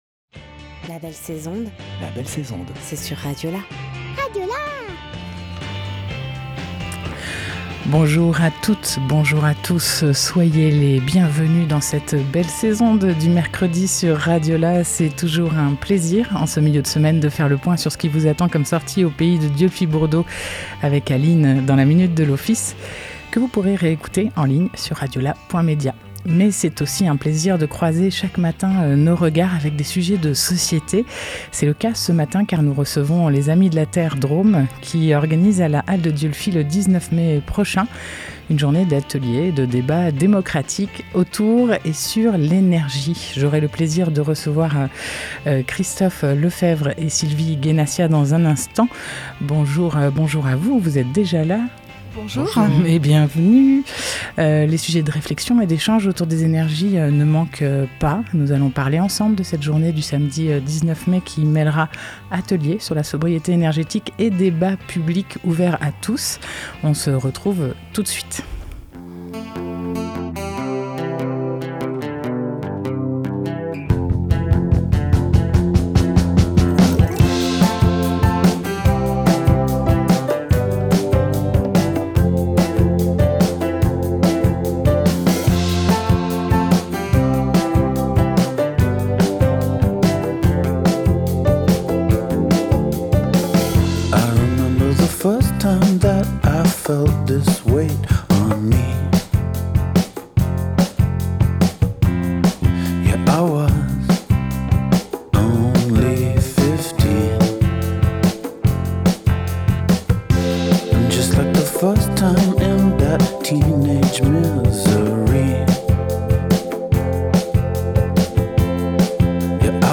10 mai 2023 12:09 | Interview, la belle sais'onde